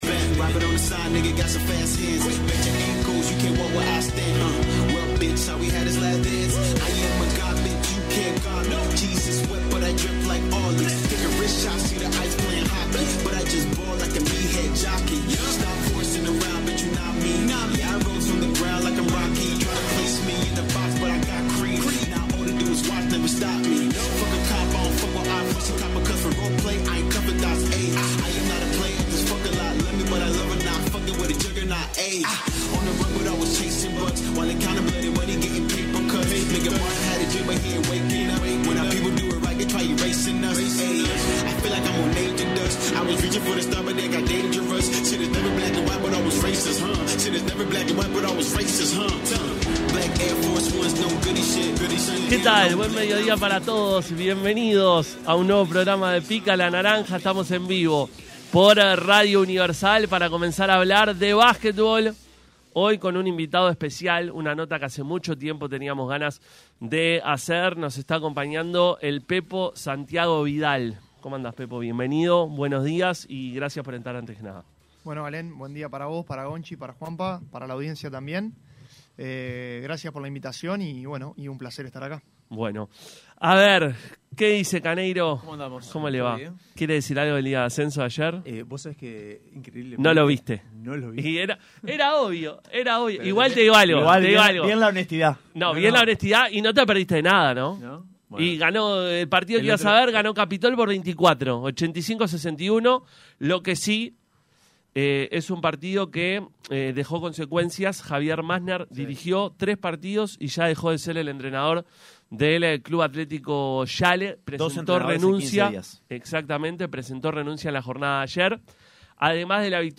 visitó los estudios de Radio Universal y habló de todo con el equipo de Pica La Naranja.